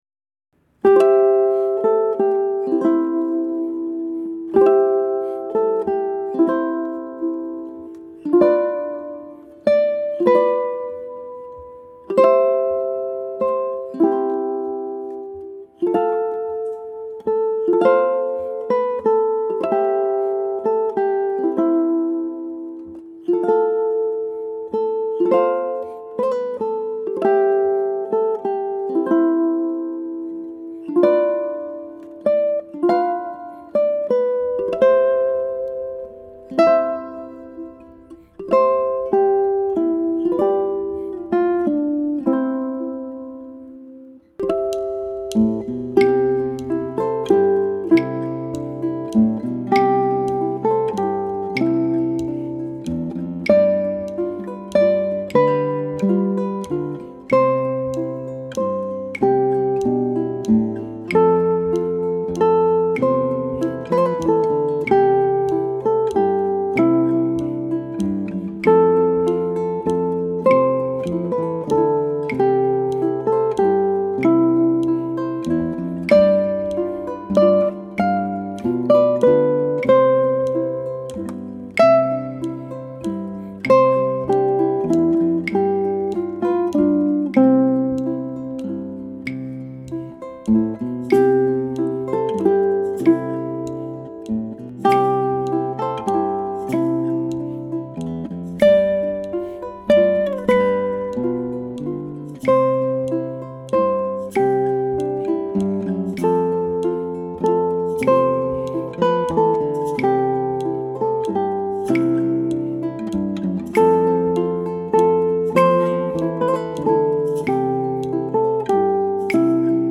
Apply a gentle accent to the downbeat to enhance the triple meter feel.
For the accompaniment, I began with a simple thumb strum, played once per measure. As the piece gained momentum, I transitioned to a gently rippling P-i-m-a-m-i arpeggio in each measure.
ʻukulele